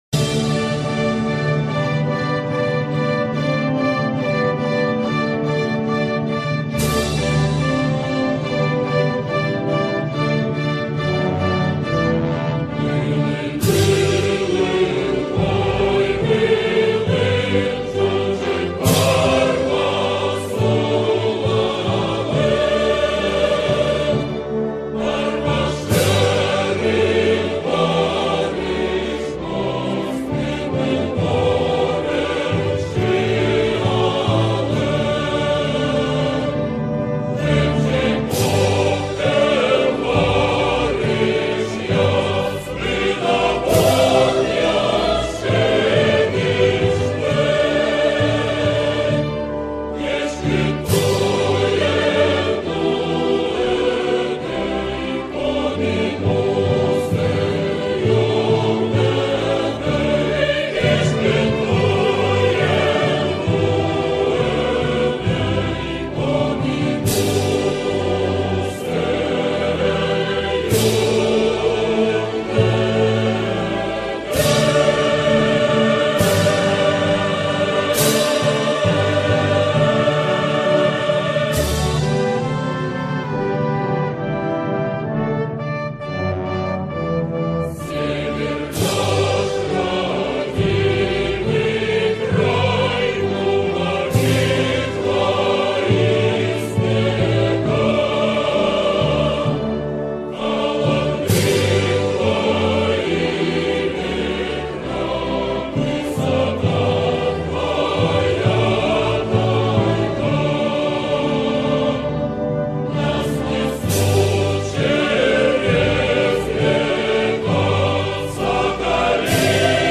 торжественное музыкальное произведение
с текстом